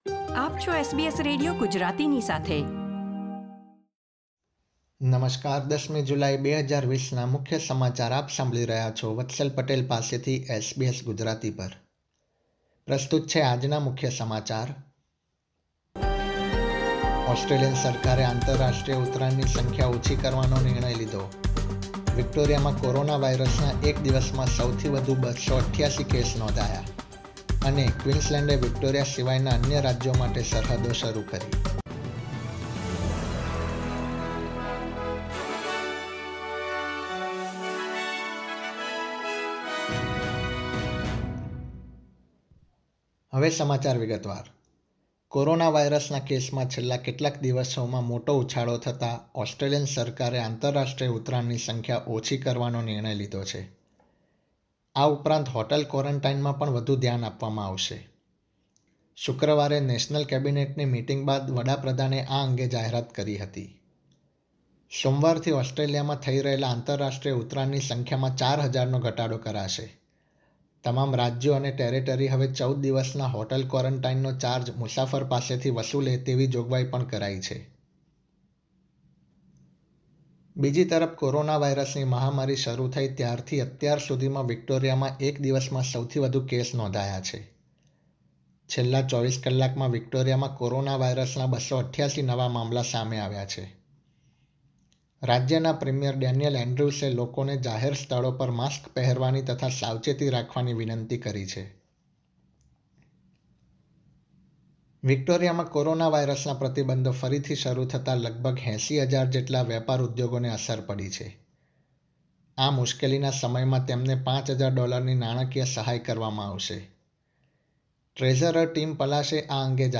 SBS Gujarati News Bulletin 10 July 2020